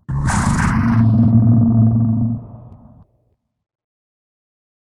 PixelPerfectionCE/assets/minecraft/sounds/mob/horse/zombie/idle2.ogg at 34728a9bcfbd9521e6ecddce805d6ca134f5efe5